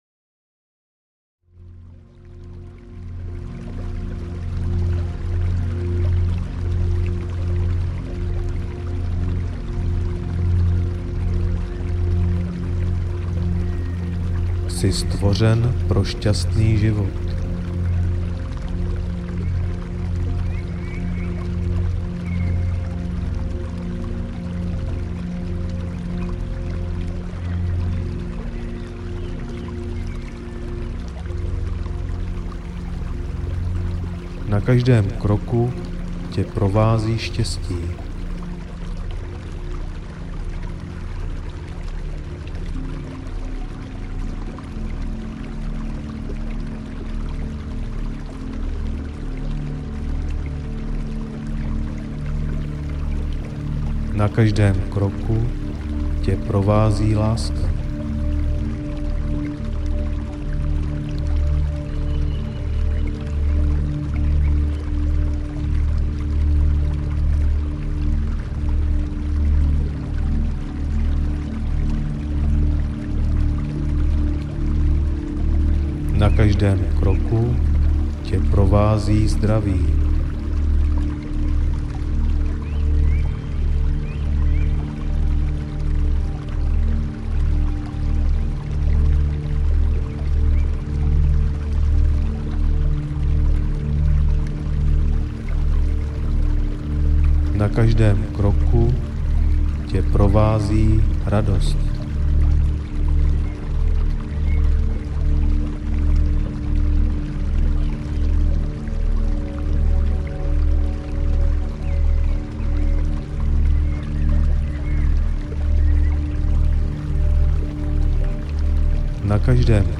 Motivační spánek pro muže audiokniha
Ukázka z knihy